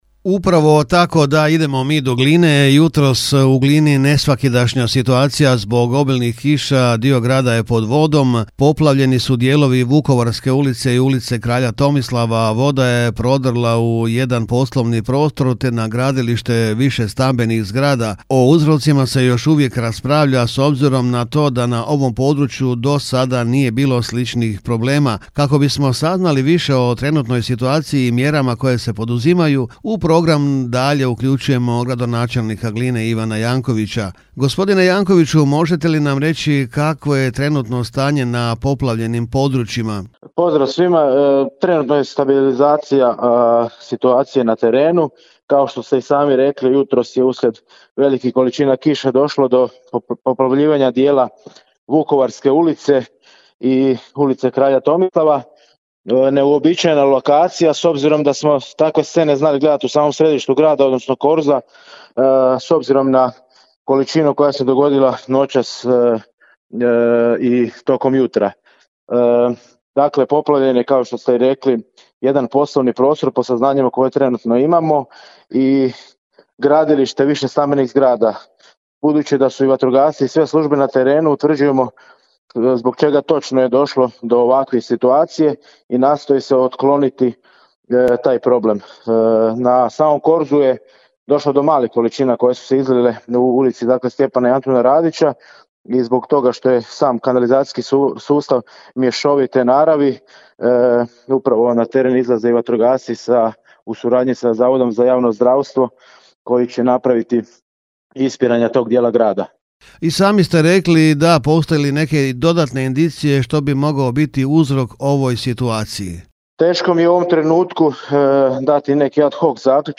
UZIVO-poplava-glina.mp3